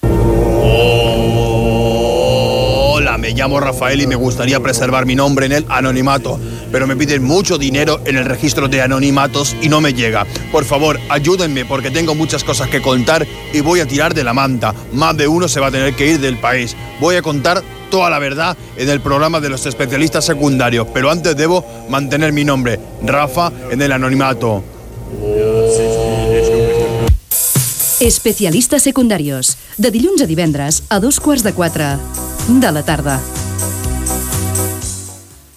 Promoció del programa
Entreteniment